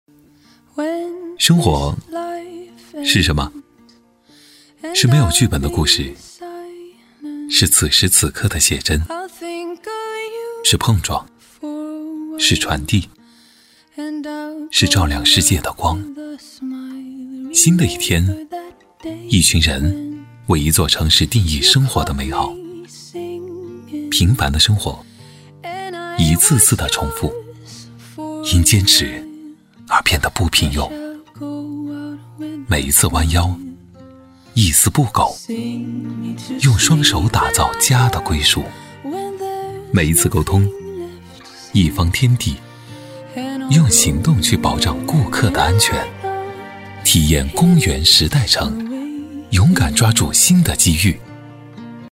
• 房地产广告配音
男139-房地产【光影时代城 浪漫随和】
男139-房地产【光影时代城 浪漫随和】.mp3